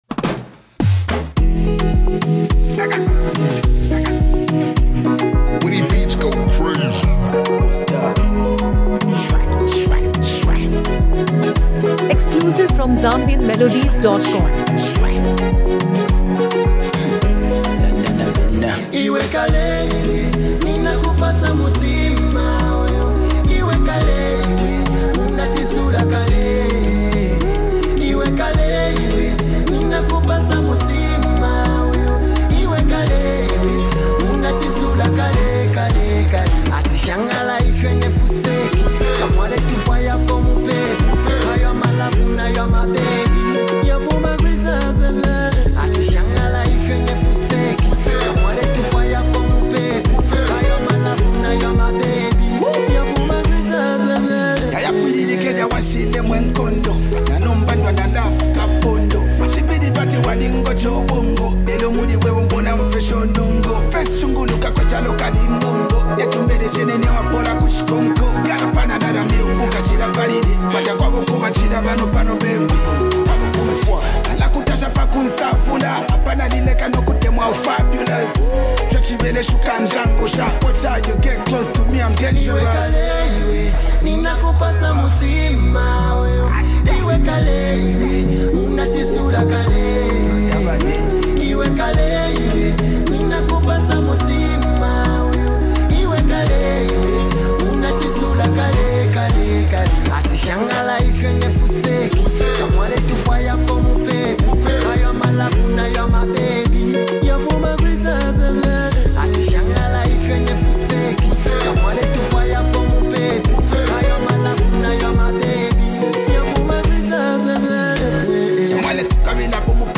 Zambian Music
energetic release
one of Zambia’s most influential hip-hop artists.